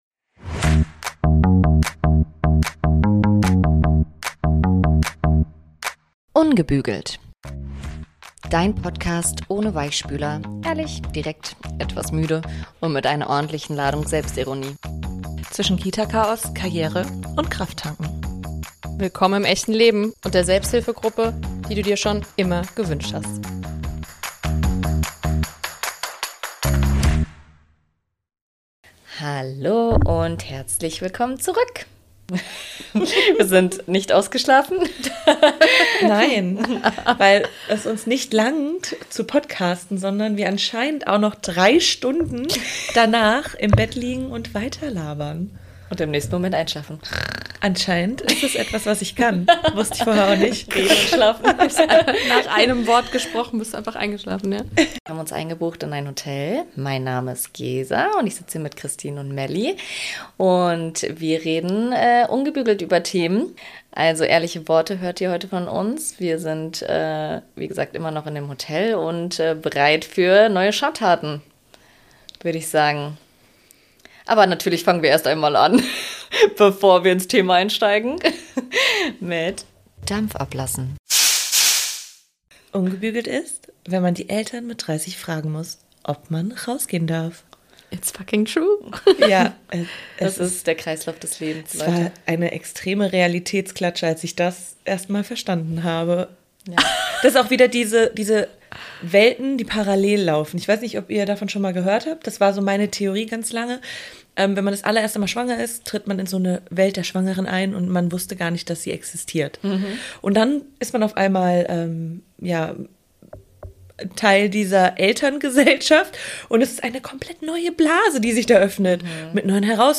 Eine authentische Gesprächsfolge über Bedürfnisse, Grenzen, Freiheit und die Kunst, wieder bei sich selbst anzukommen.